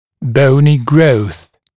[‘bəunɪ grəuθ][‘боуни гроус]рост костных тканей